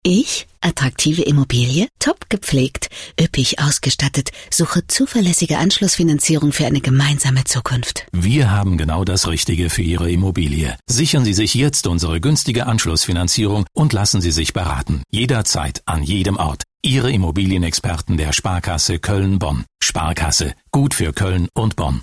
Sparkasse KölnBonn - Funkspots - KreativRealisten | Agentur für strategisches Marketing
sparkasse-koelnbonn_funkspot_immobilien.mp3